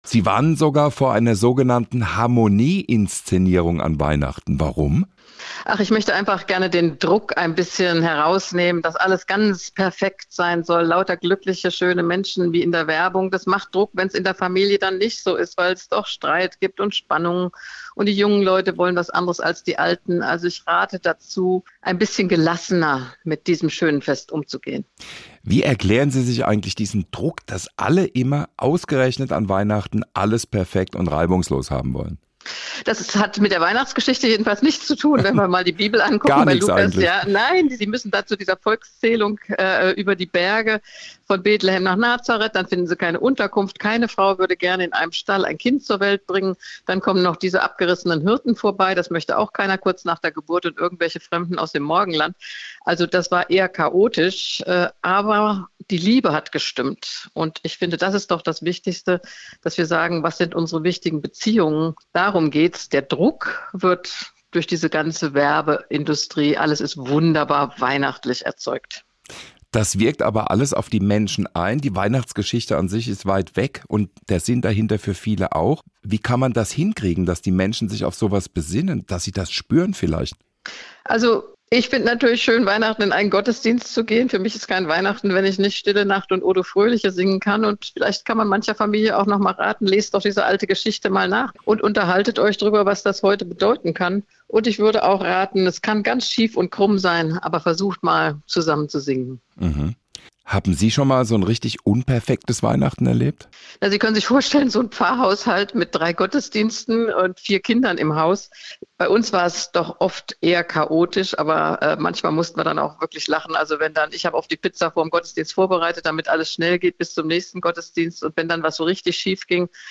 Die ehemalige Vorsitzende der Evangelischen Kirche in Deutschland hat mit uns über ihre Gefühle zu Weihnachten gesprochen.